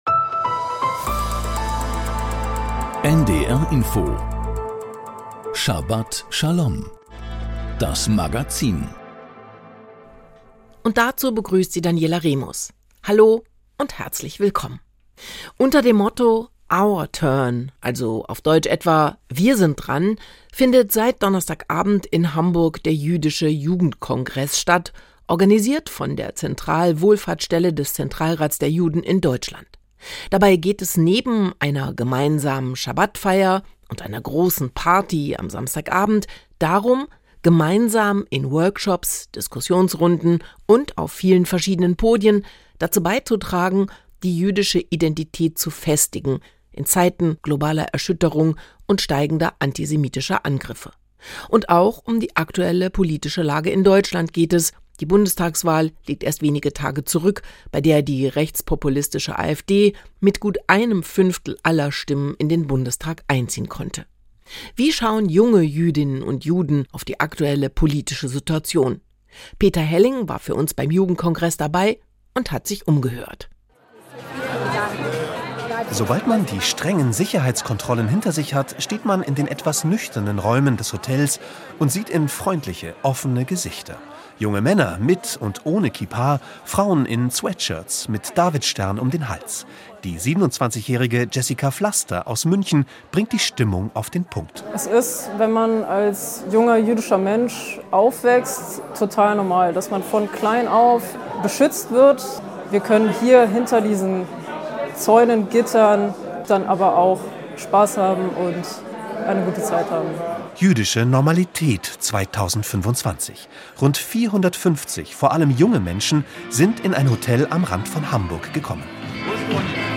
Das Magazin Schabat Schalom berichtet aus dem jüdischen Leben mit Nachrichten, Interviews, Berichten und Kommentaren. Dazu die Wochenabschnittsauslegung der Thora.